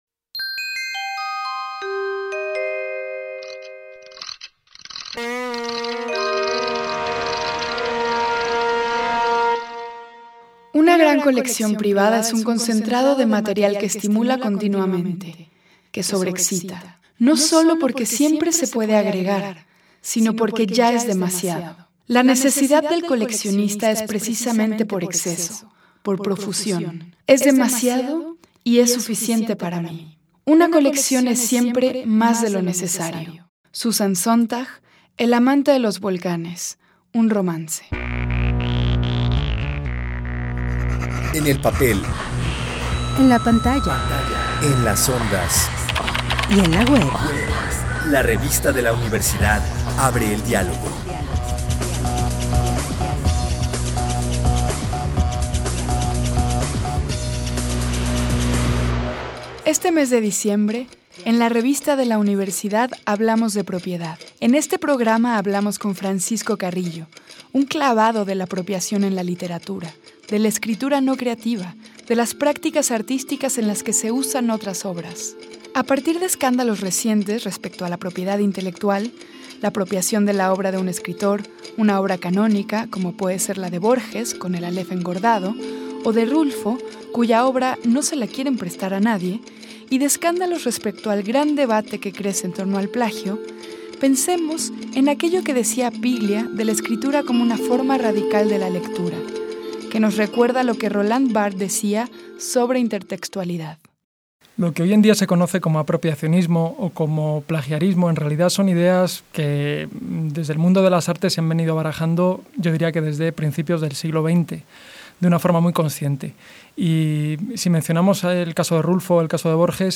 Fue transmitido el jueves 16 de noviembre de 2017 por el 96.1 FM.